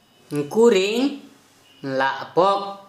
Dialect: Hill-Plains mixed/transitional